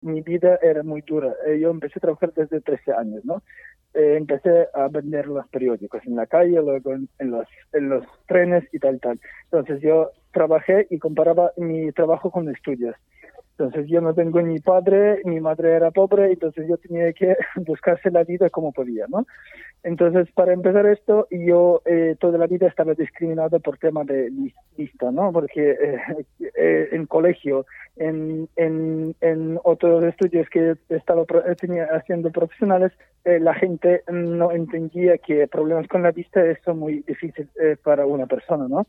La escalada de violencia no había hecho más que empezar y multiplicarse desde que se realizara esta entrevista, vía teléfono móvil, en la tarde del jueves 10 de marzo.